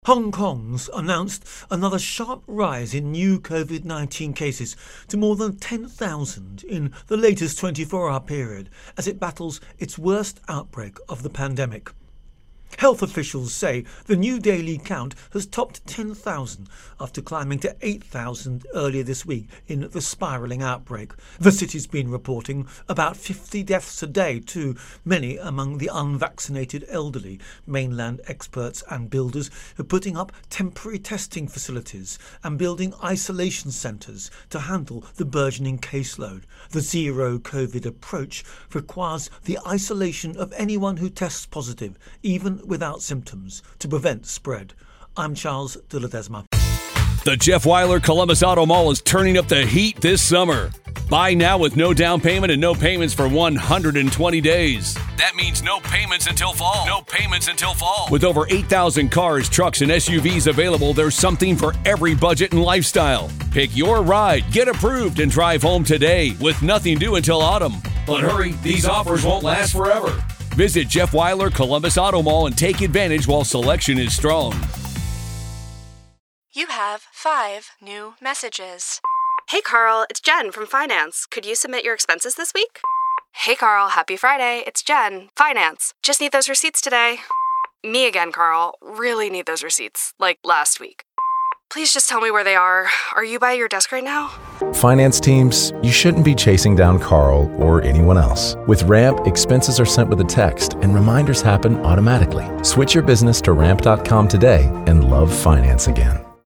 Virus Outbreak-Hong Kong Intro and Voicer